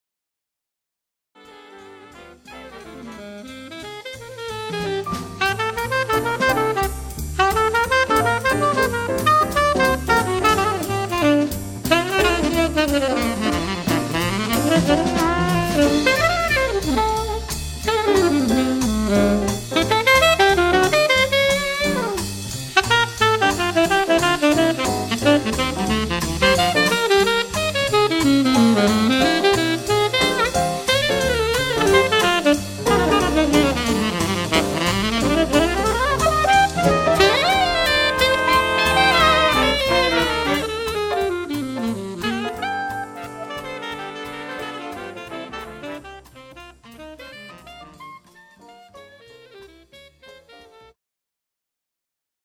The Best In British Jazz
Recorded at The Sound Cafe, Midlothian